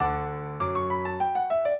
minuet0-7.wav